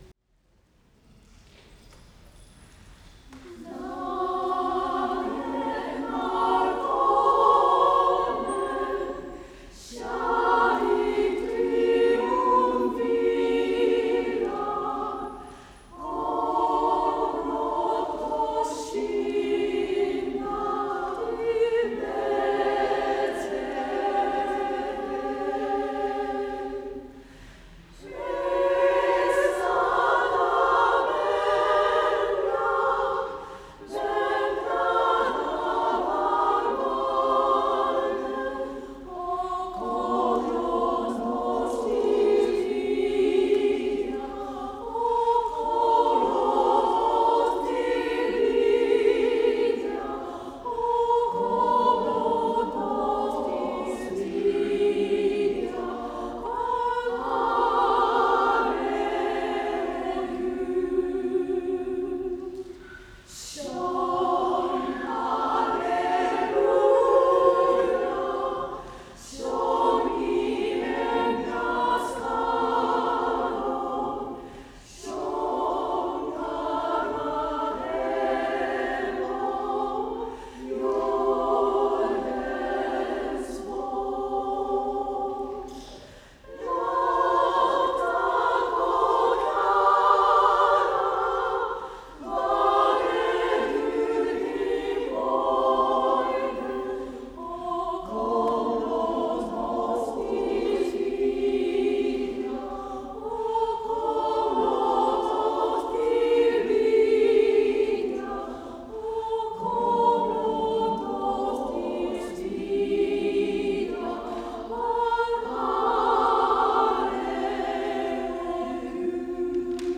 団員掲示板 | 女声アンサンブル クラルス・ヴォ―チェ